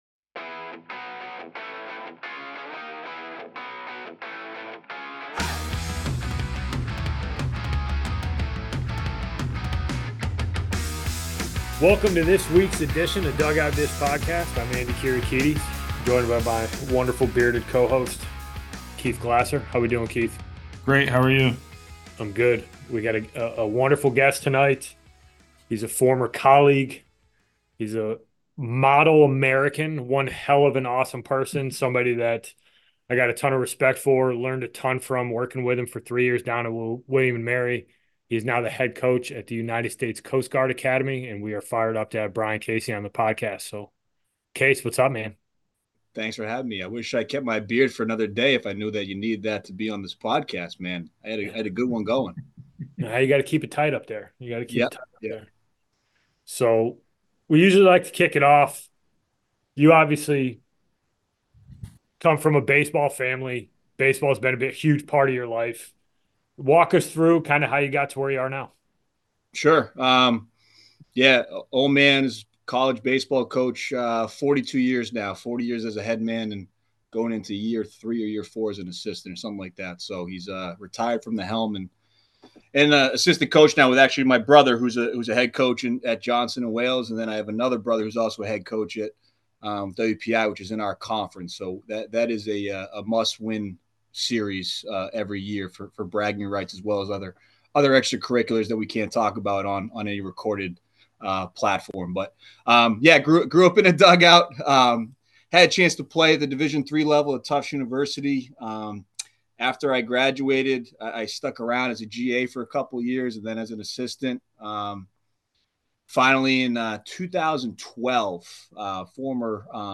Episode 53: Interview